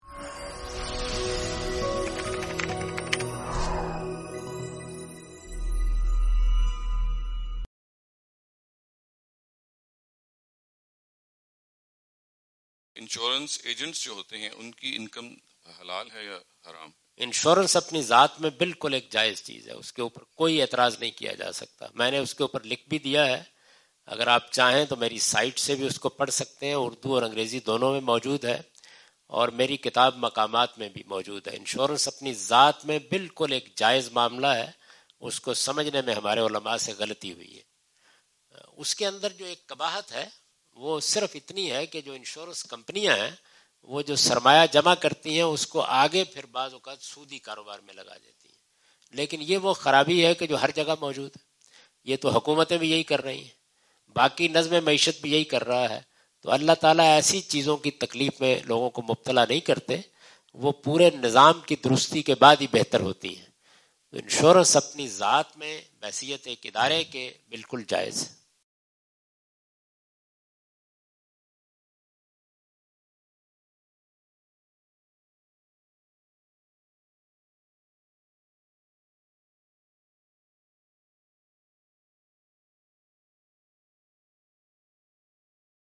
Javed Ahmad Ghamidi answer the question about "Is Income of Insurance Agents Lawful?" during his US visit on June 13, 2015.
جاوید احمد غامدی اپنے دورہ امریکہ 2015 کے دوران سانتا کلارا، کیلیفورنیا میں "کیا انشورنس ایجنٹس کی کمائی جائز ہے؟" سے متعلق ایک سوال کا جواب دے رہے ہیں۔